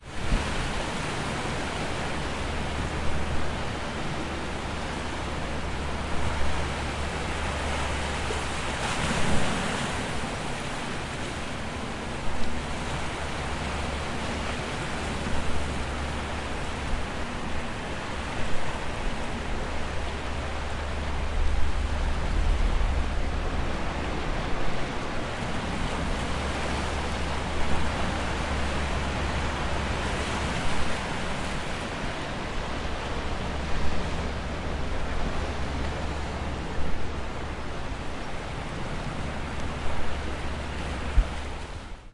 Tag: 沙滩 海浪 打破波 海洋 海岸 海岸 海岸 海洋 海滨